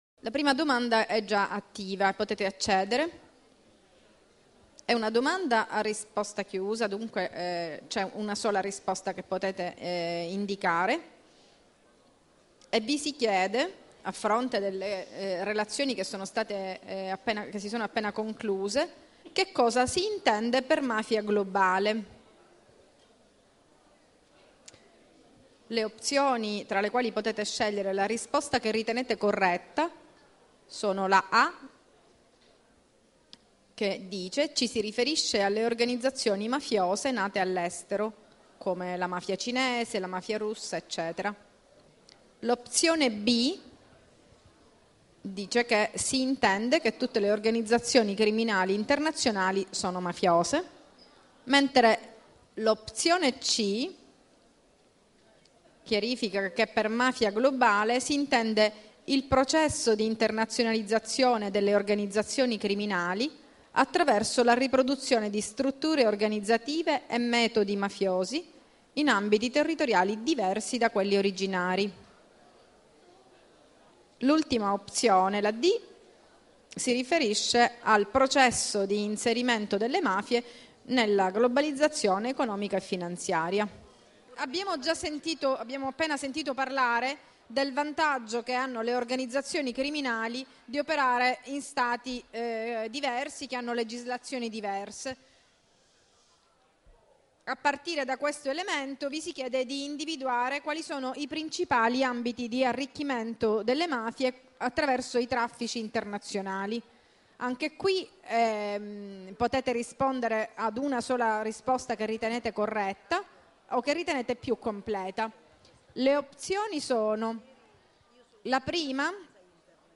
Questionario e Dibattito 1 h 06' 24'' 229